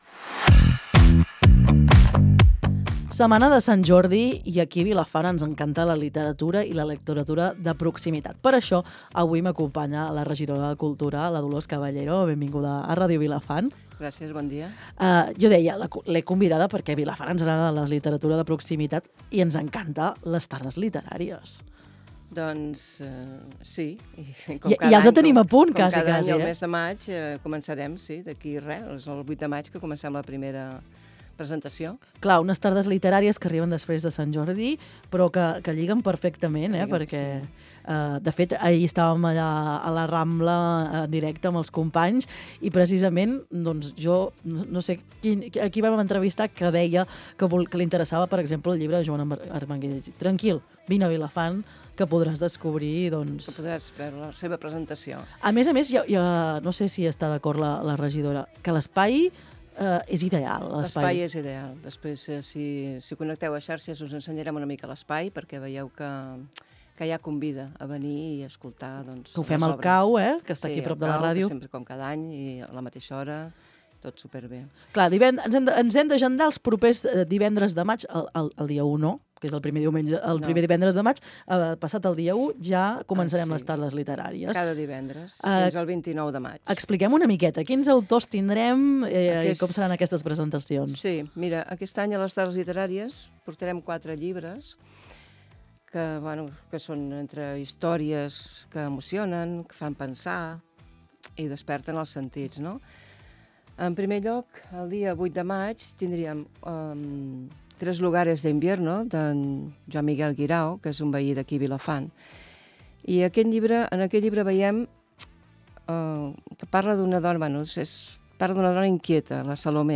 A Les Veus del Matí hem parlat amb la regidora de Cultura de l’Ajuntament de Vilafant, Dolors Caballero, sobre una nova edició de les tardes literàries que arriba al municipi.
Durant l’entrevista, Caballero ha explicat en què consisteix aquesta iniciativa, que busca fomentar la lectura i la cultura amb diferents activitats i propostes obertes a la ciutadania.